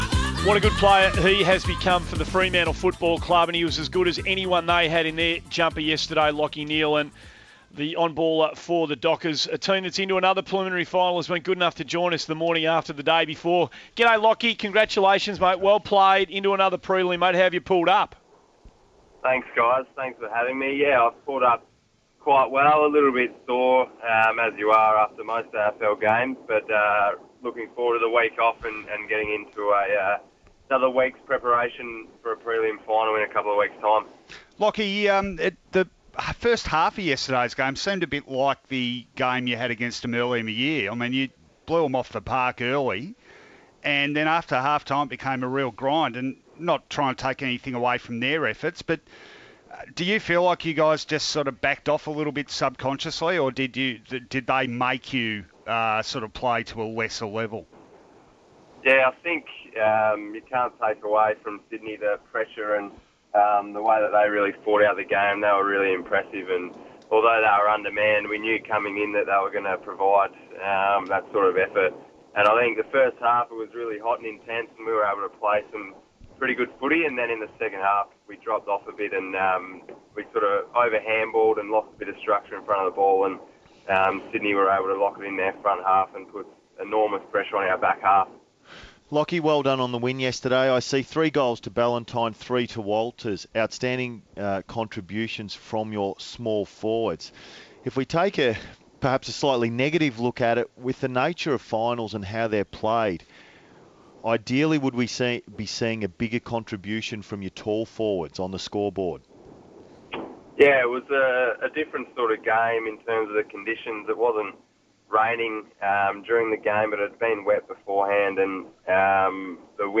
Lachie Neale spoke to SEN the day after Freo's win over Sydney